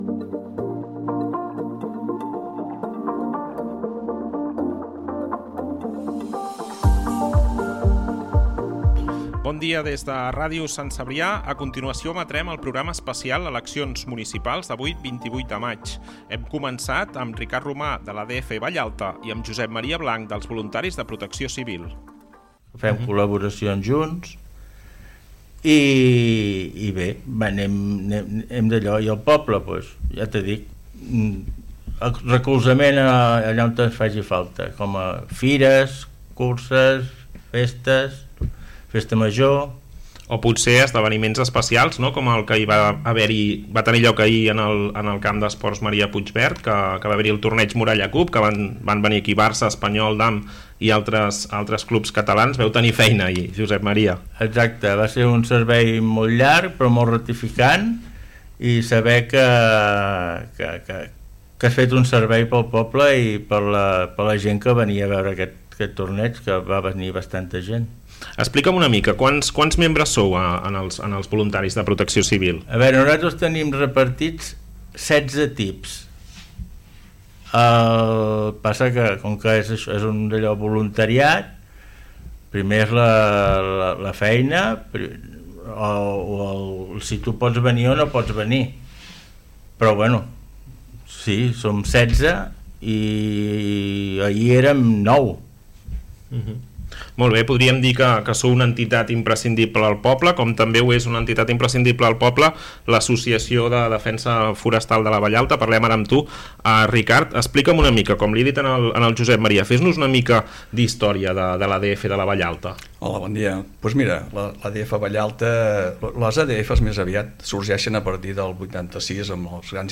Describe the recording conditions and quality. Connexió amb el col·legi electoral de la localitat. FM